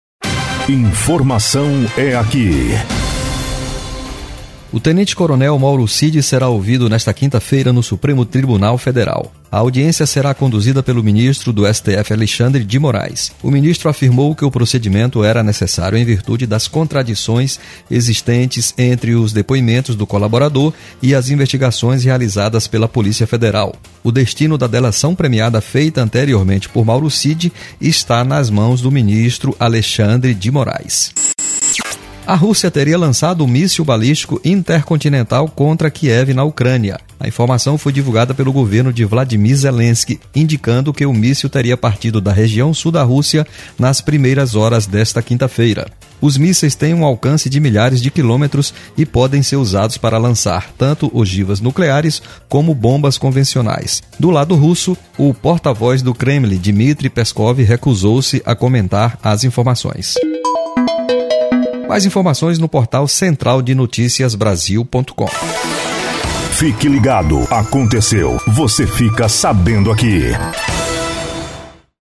Todos os Plantões de Notícias
Repórter